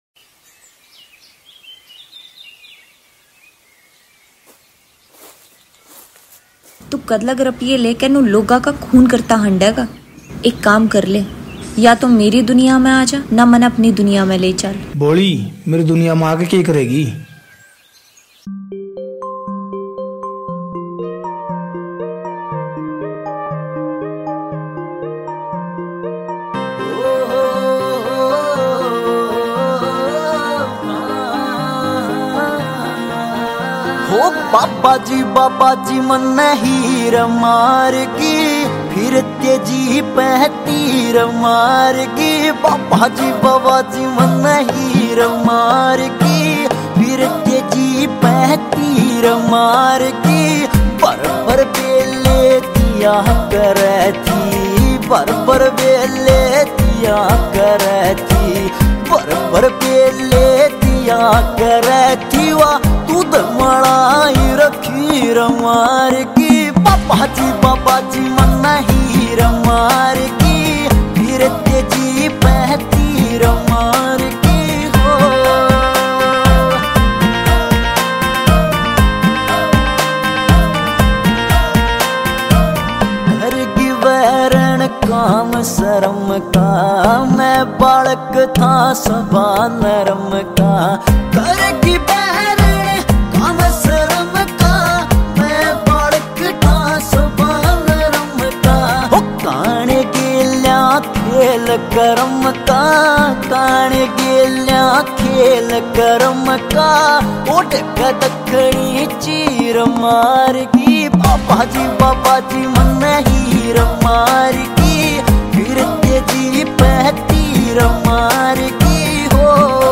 Releted Files Of Haryanvi